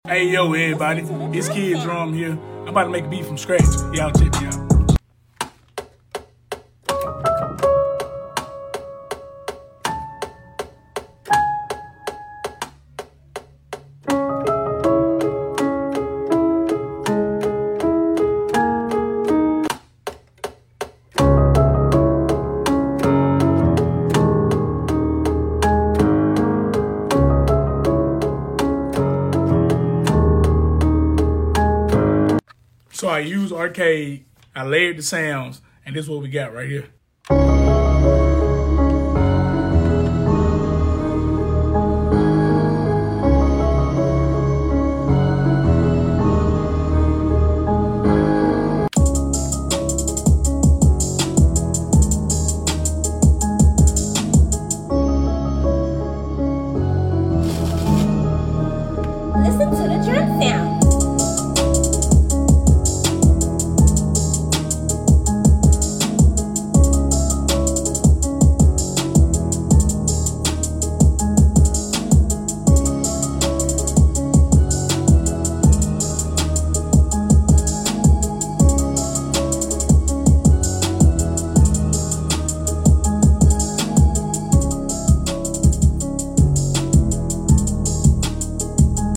Layering sounds make the beat sound full and not too busy.